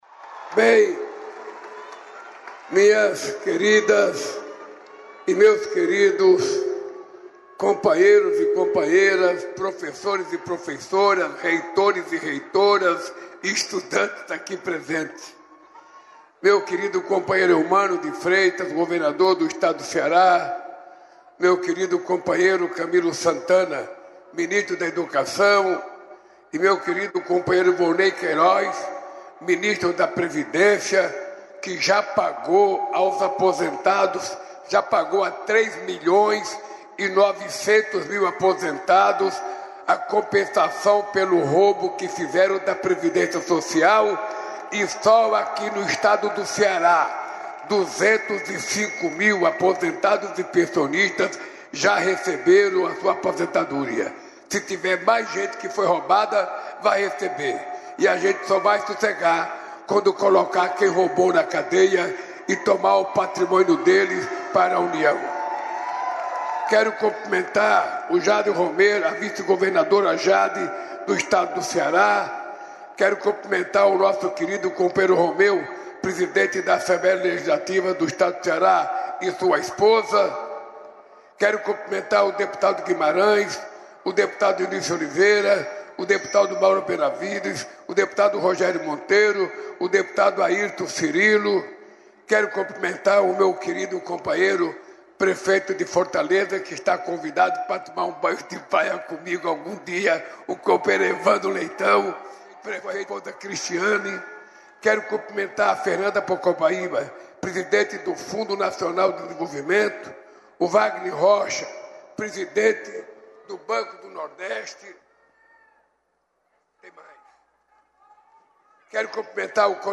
Mesa-redonda com vários líderes globais, moderada pelo presidente brasileiro e pelo presidente espanhol, Pedro Sánchez, tratou da defesa da democracia e do combate a extremismos; ouça a fala de abertura do presidente Lula no evento, nesta terça-feira (24), em Nova York.